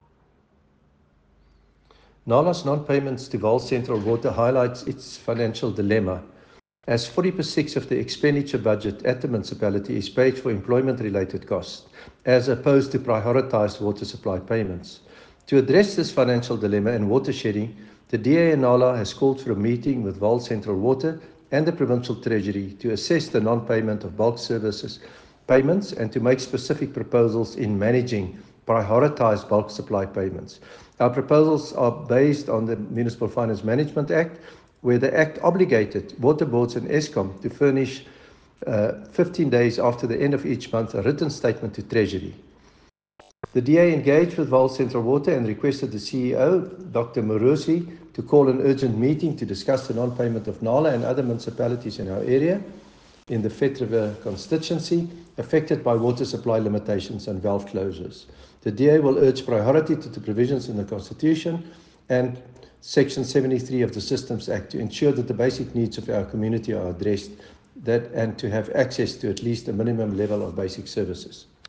English and Afrikaans soundbites by Cllr David Ross and